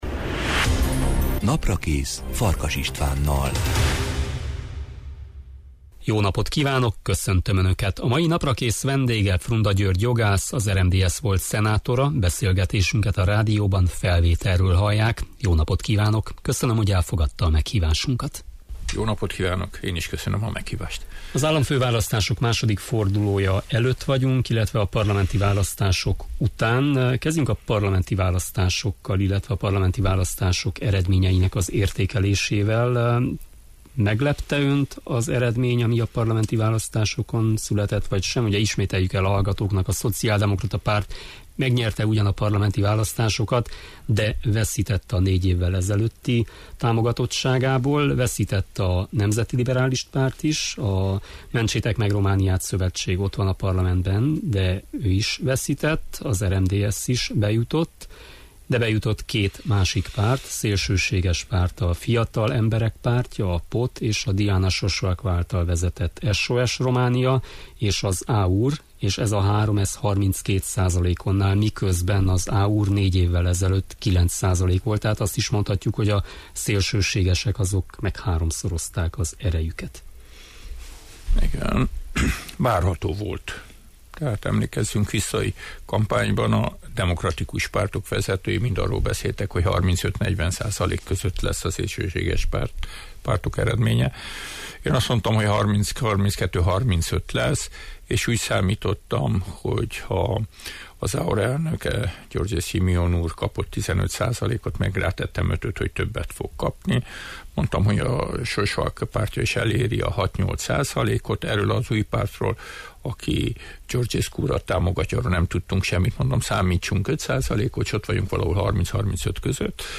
Frunda György jogász, volt szenátor a vendégem.